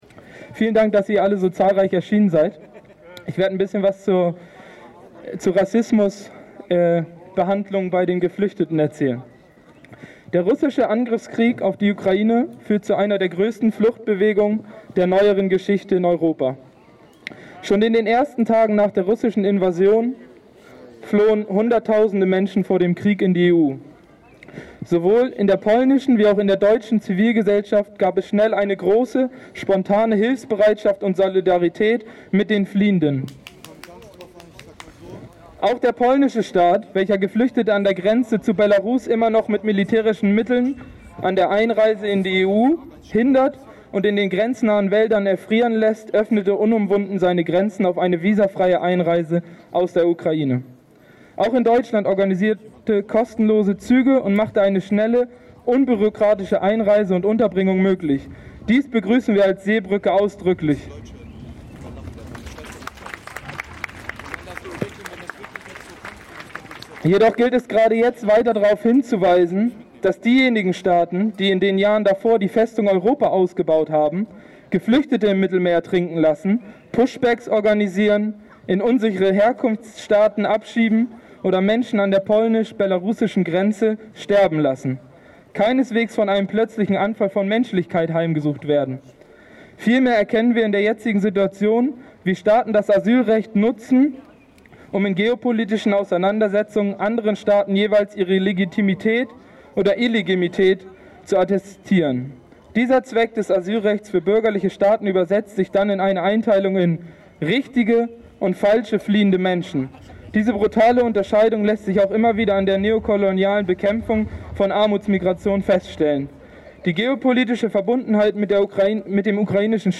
Eine Kundgebung auf dem Platz der Alten Synagoge.